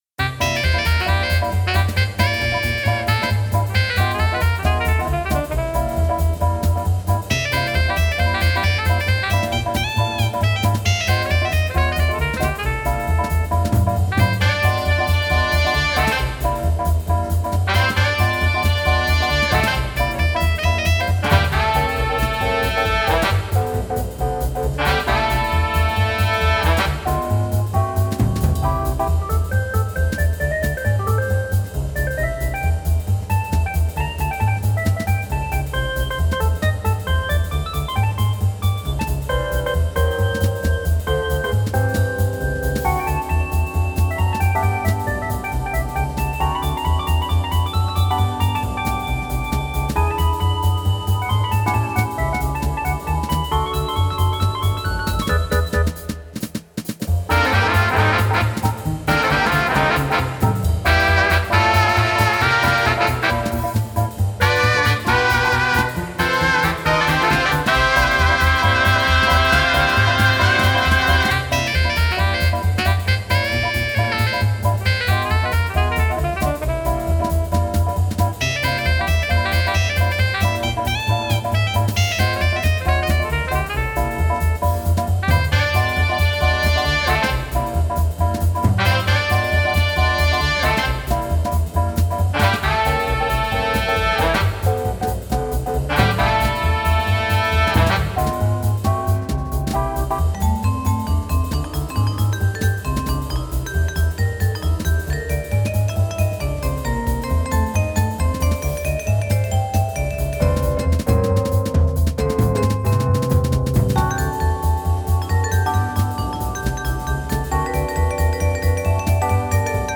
It’s a jazzy piece, check it out!
Filed under: MIDI Music, Remix, Video Game Music